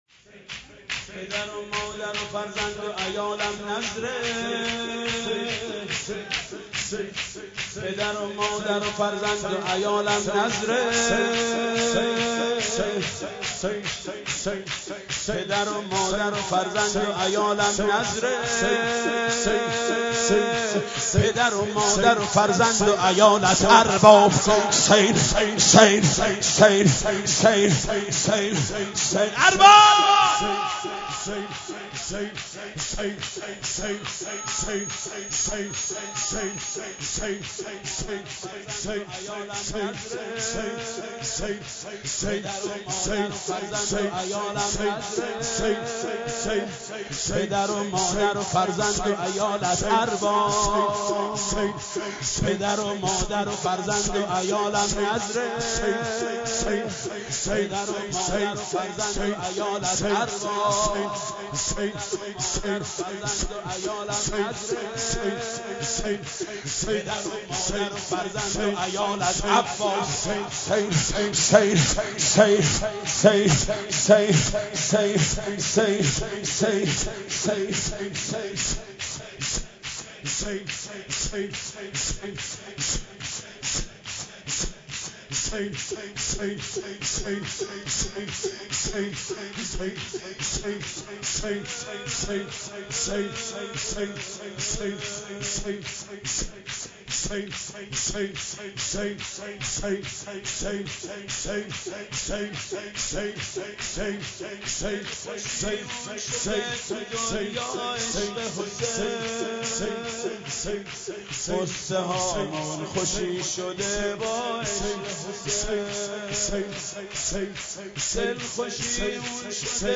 اشعار امام حسین علیه السلام به همراه سبک با صدای حاج محمود کریمی/شور -( پدر و مادر و فرزند و عیالم نذر )